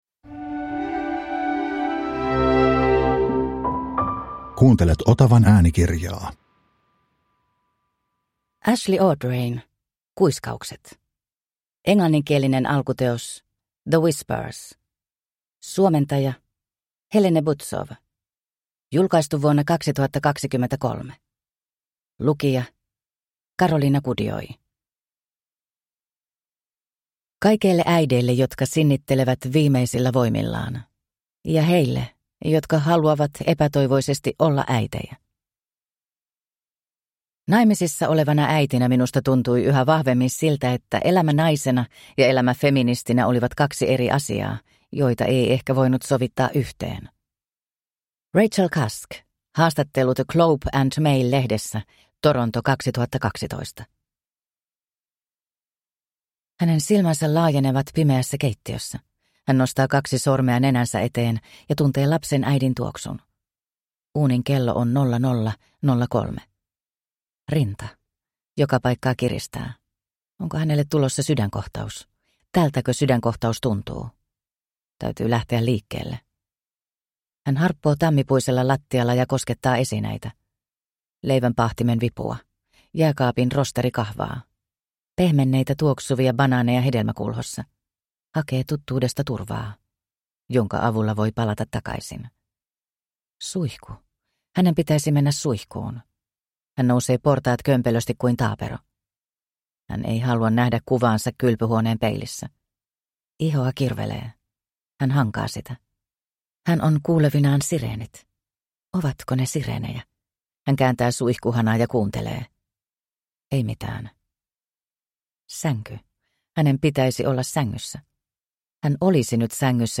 Kuiskaukset – Ljudbok – Laddas ner